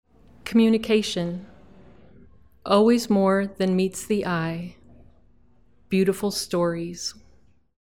Audio Tour - Power of Poetry